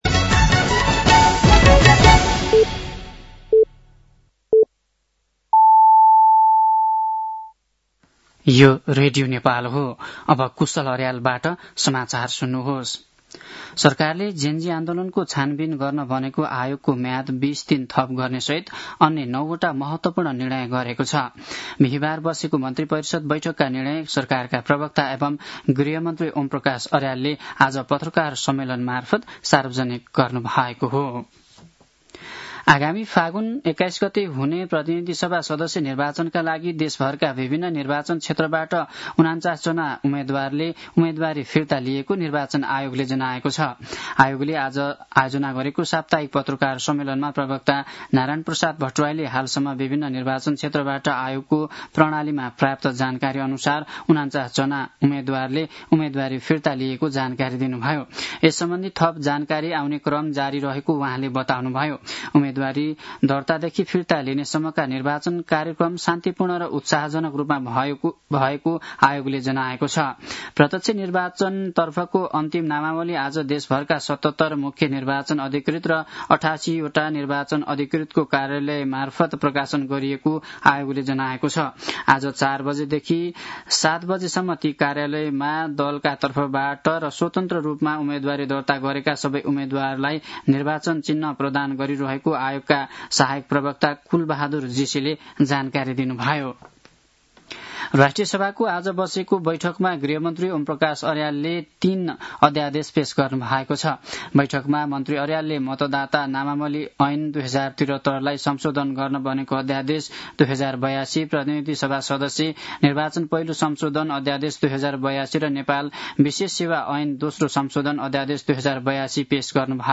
साँझ ५ बजेको नेपाली समाचार : ९ माघ , २०८२
5-pm-nepali-news-10-09.mp3